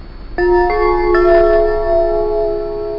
Chime Sound Effect
Download a high-quality chime sound effect.
chime-1.mp3